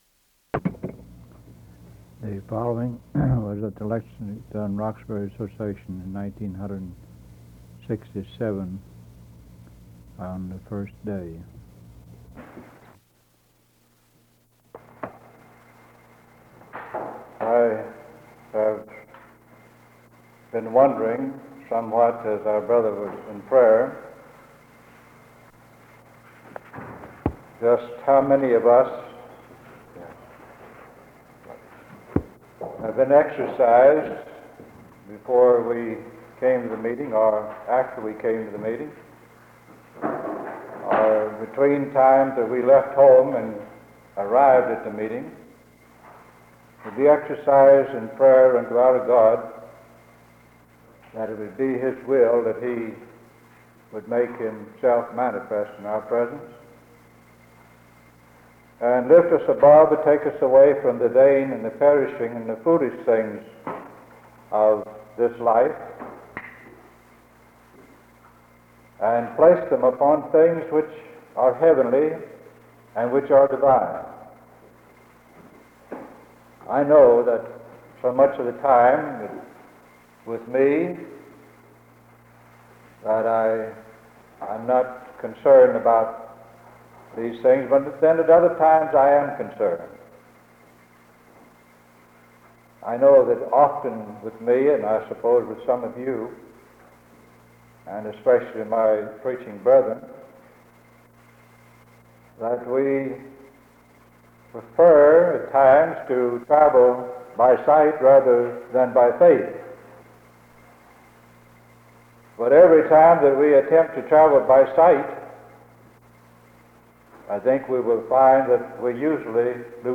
Sermon from the 1967 Lexington-Roxbury Association "on the first day"